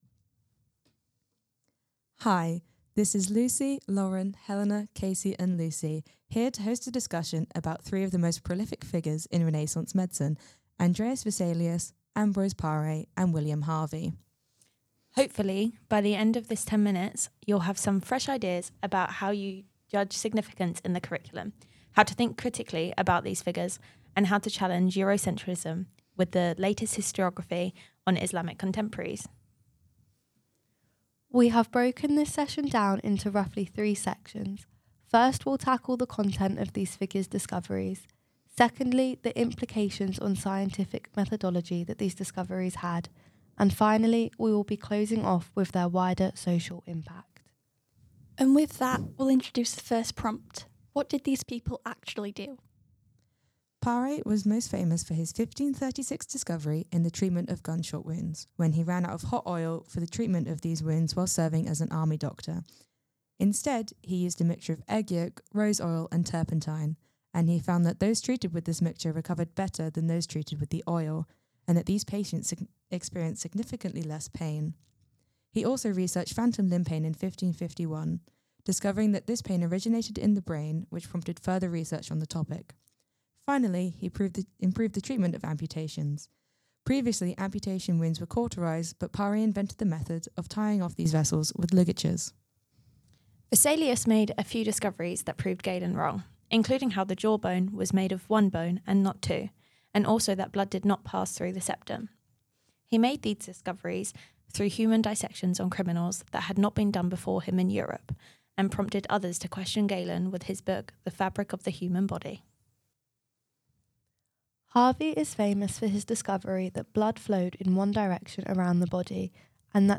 In this podcast five students discuss three of the most prolific figures in Renaissance medicine: Andreas Vesalius, Ambroise Paré and William Harvey.